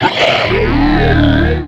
Cri de Tarinorme dans Pokémon X et Y.